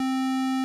Square wave
S1's feedback is used to push the effect further, lowering it will just muffle it a bit more.
Audio: YM2612 square wave